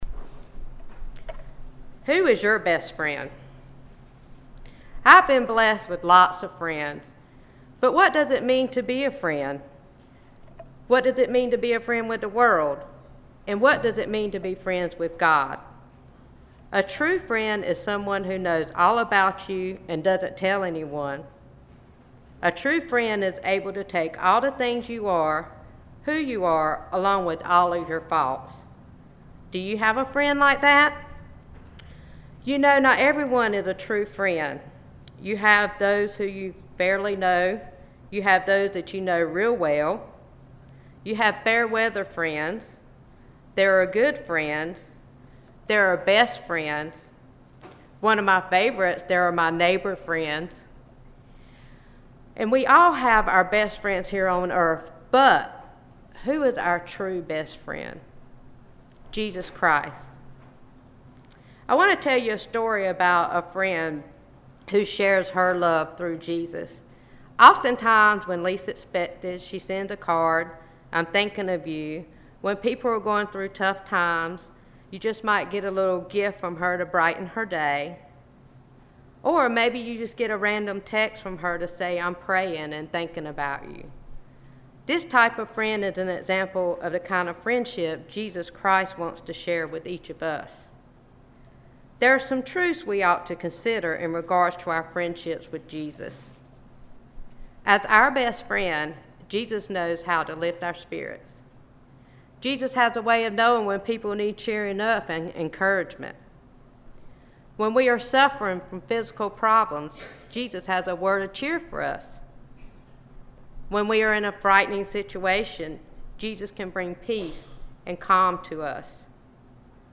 Message
8-12-sermon.mp3